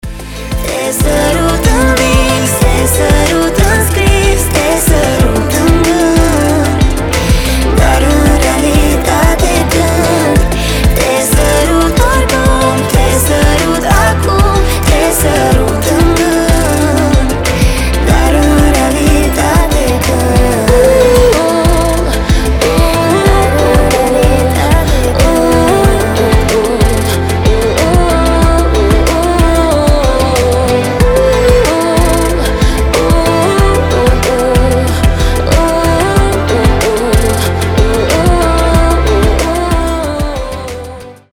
• Качество: 320, Stereo
поп
красивая мелодия
дуэт
красивый женский голос
баллада